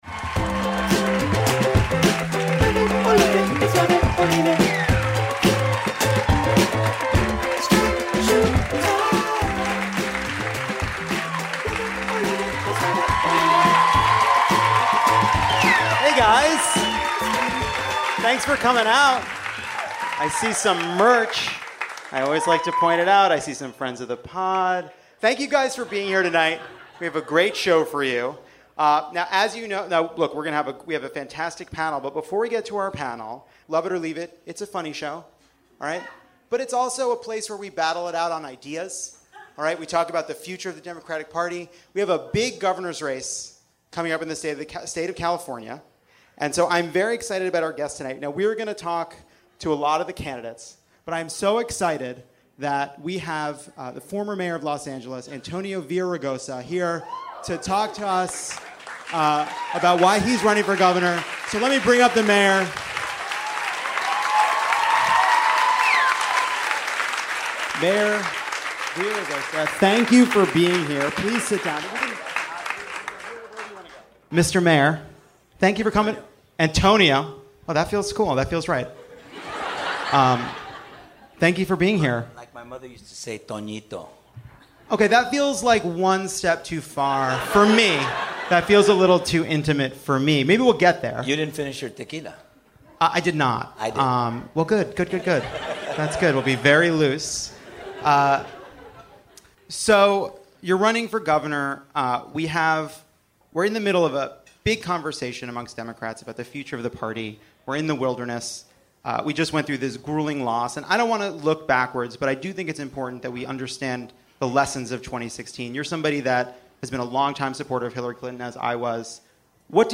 Former LA Mayor Antonio Villaraigosa on his run for Governor. Jeff Ross, Wil Wheaton, and Sabrina Jalees discuss ethics resignations and voter fraud; Tommy Vietor joins for the "Vietor Detour" to break down a big foreign policy week; and the rant wheel turns into a debate over civility in politics.